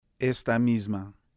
La fricativa alveolar sorda y sonora en [×stamÔ¸ma].